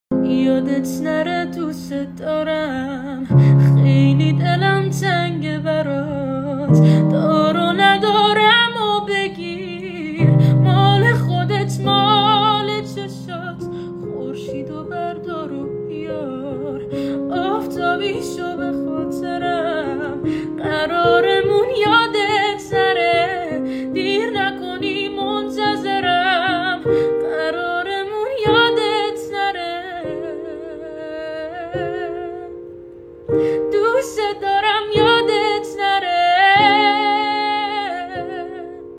با صدای زن (تمام خوانندگان)